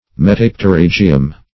Search Result for " metapterygium" : The Collaborative International Dictionary of English v.0.48: Metapterygium \Me*tap`te*ryg"i*um\, n. [NL., fr. Gr.